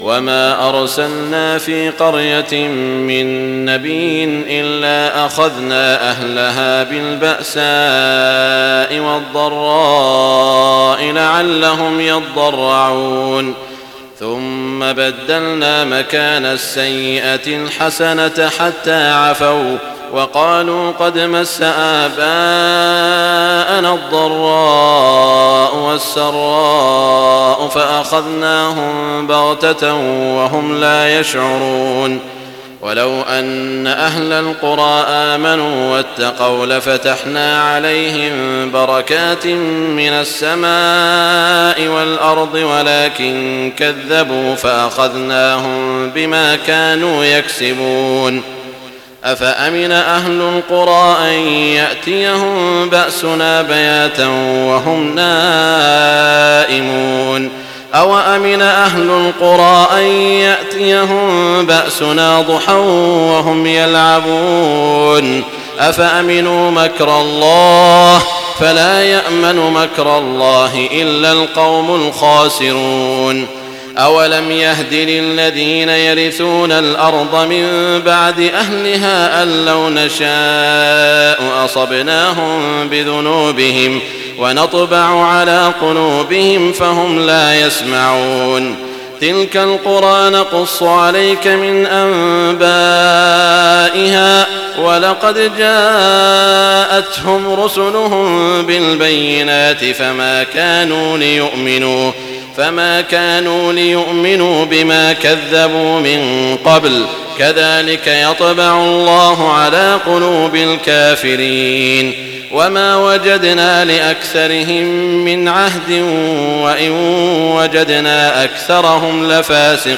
تهجد ليلة 29 رمضان 1419هـ من سورة الأعراف (94-188) Tahajjud 29 st night Ramadan 1419H from Surah Al-A’raf > تراويح الحرم المكي عام 1419 🕋 > التراويح - تلاوات الحرمين